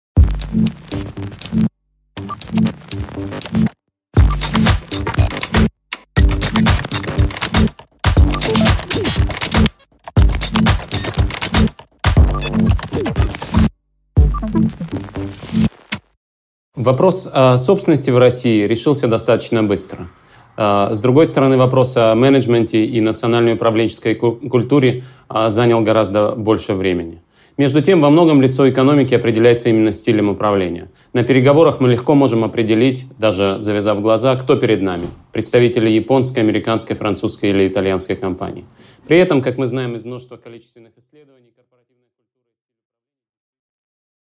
Аудиокнига Битва при Сити. Национальная школа корпоративного управления | Библиотека аудиокниг